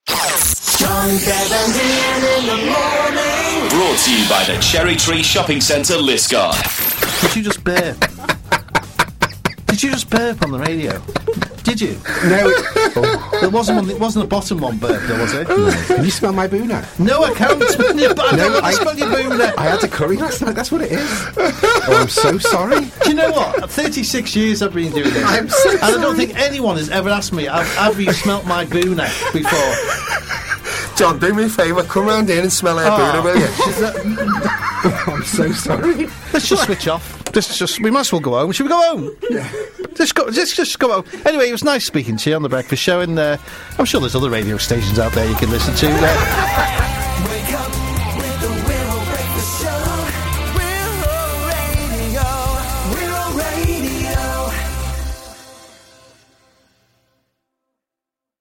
Just the usual mishap for me on the Wirral Radio Breakfast Show. Can't live this one down!